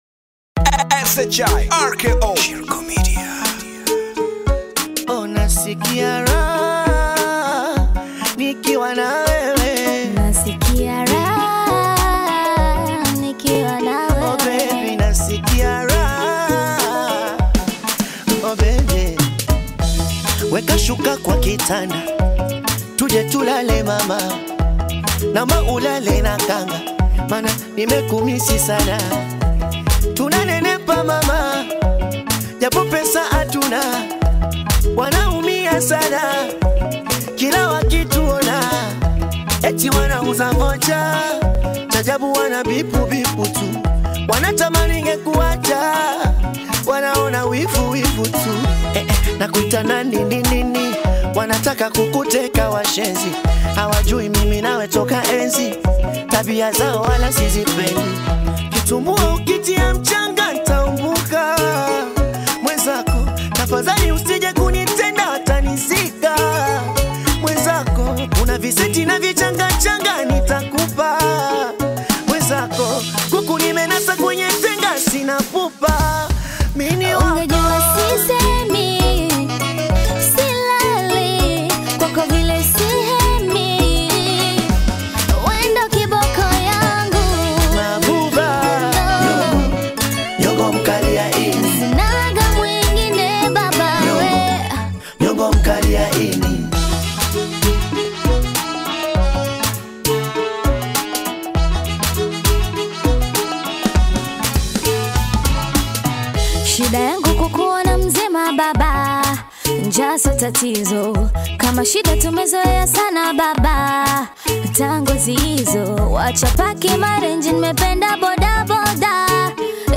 classic Afro-Pop/Bongo Flava collaboration
warm, love-driven melodies with affectionate Swahili lyrics